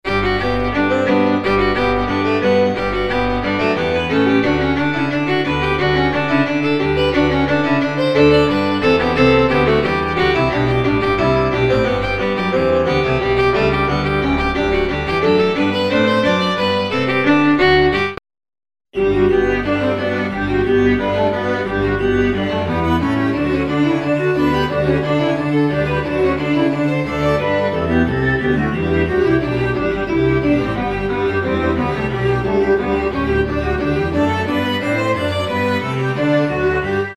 Каждый из предложенных файлов содержит два варианта звучания одной и той же мидяхи- сначала на софто-отстое, потом с моего рабочего SF2.
Между ними пауза в 1 секунду.. чтоб отдышаться от каки. :-)
НИКАКОЙ дополнительной обработки не производилось.